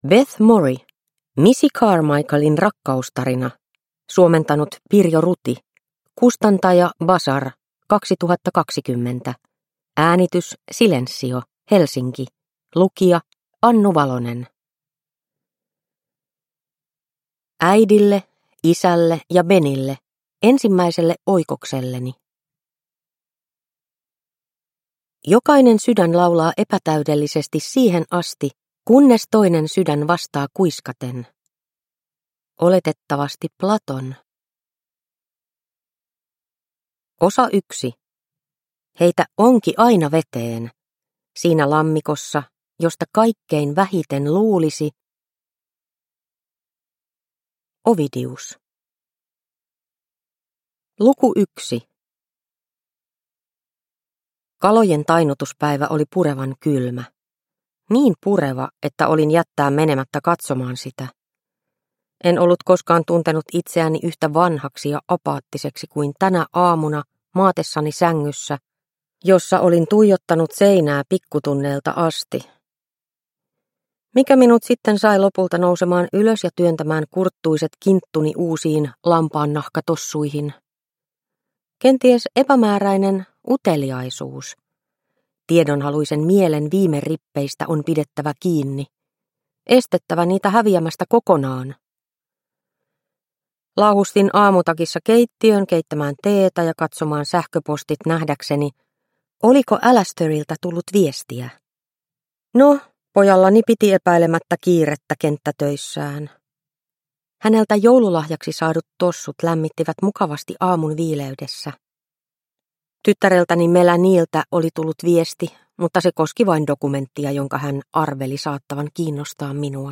Missy Carmichaelin rakkaustarina (ljudbok) av Beth Morrey